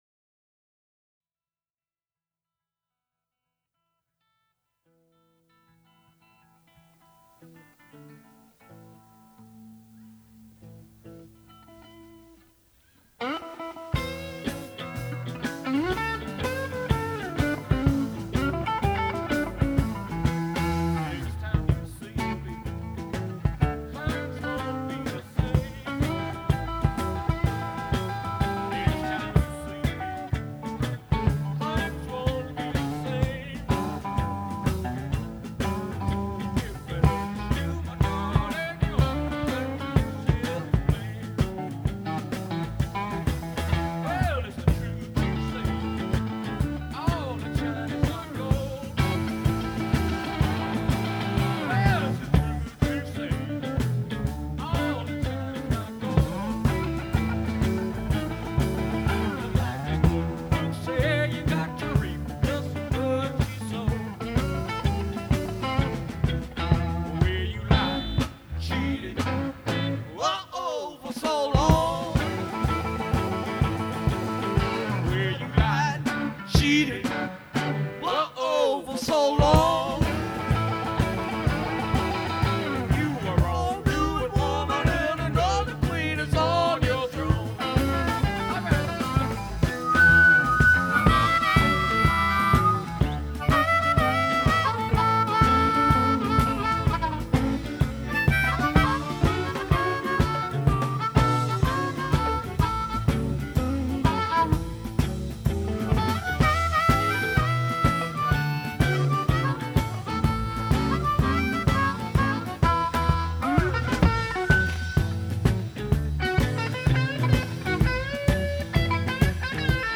St. Patrick's Day
Fox Theatre, St. Louis, MO
electric guitar (lead), vocals
drums, perc
electric bass, vocals
organ, vocals